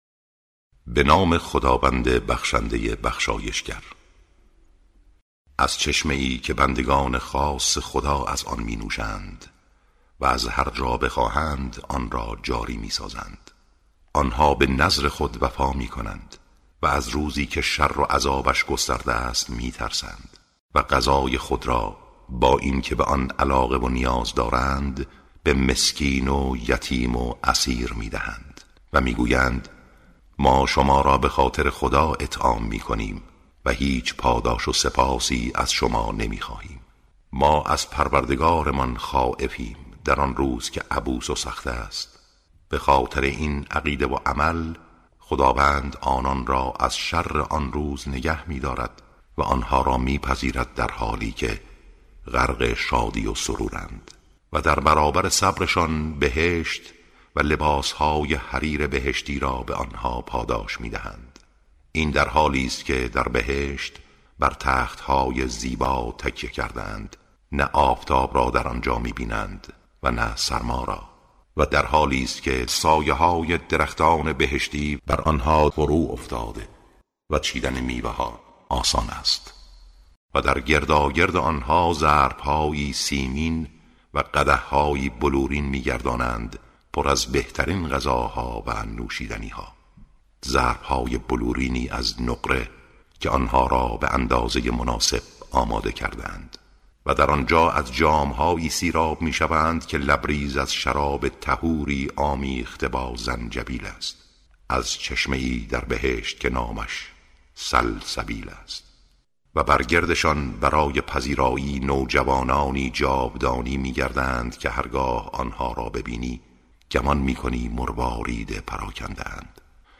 ترجمه سوره(انسان)